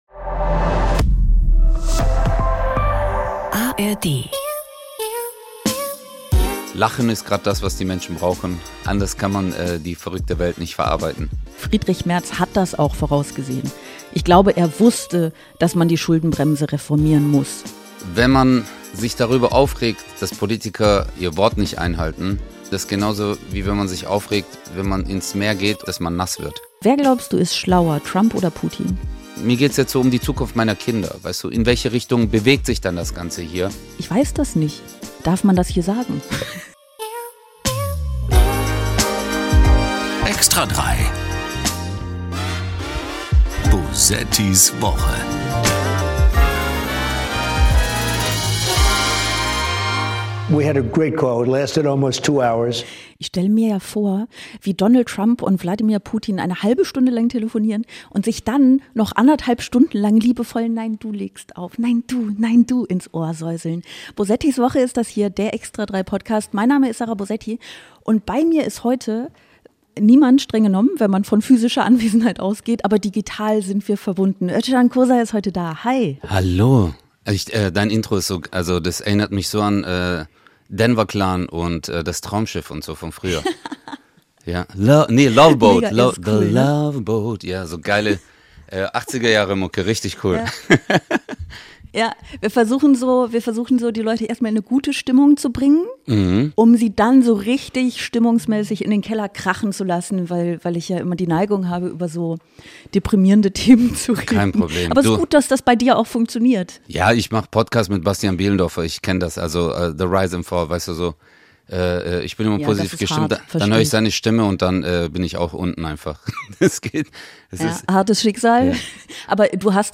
Bewaffnet mit skurrilen Tönen aus Radio, Fernsehen und Internet wird rund 45 Minuten verspottet, überspitzt, gelacht und knallhart all das kommentiert, was eben schiefläuft – genau so kümmert sich „extra 3“ seit mehr als 40 Jahren im Fernsehen um den Irrsinn der Woche.
… continue reading 73 Episoden # Komödie # NDR